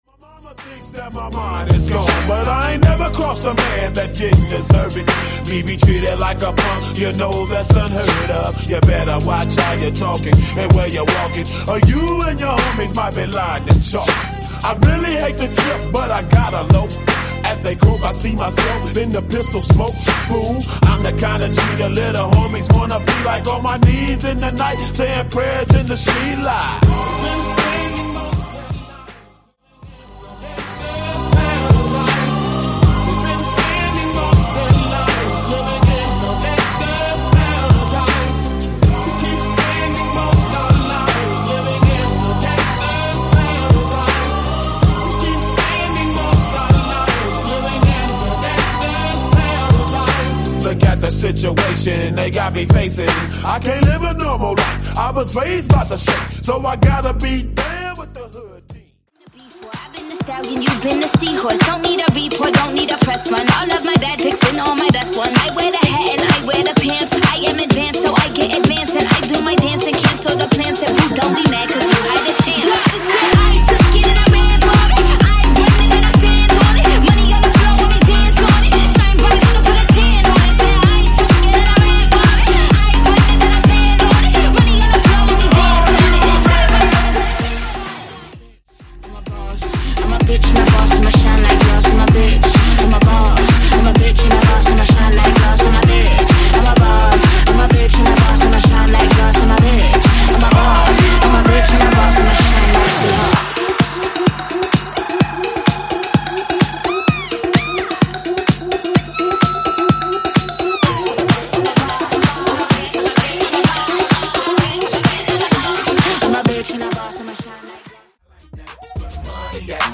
2000s 420 Dancehall Redrum) 117 Bpm
2000s Future House Bootleg